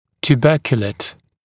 [t(j)uː’bɜːkjələt][т(й)у:’бё:кйэлэт]покрытый бугорками, бугристый